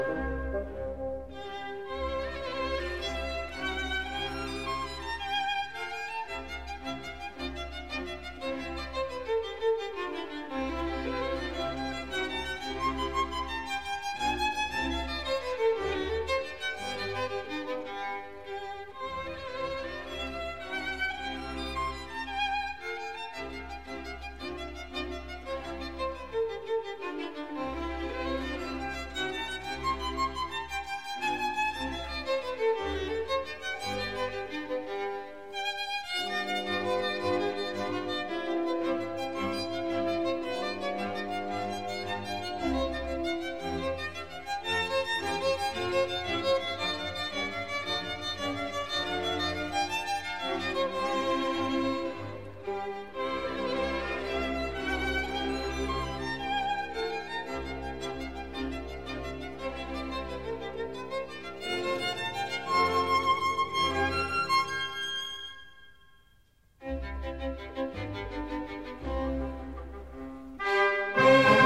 Violin: Haydn: Symphony 103 Mvt. II (Violin Solo mm. 85-107) – Orchestra Excerpts
Günther Herbig: Dresdner Philharmonie, 2008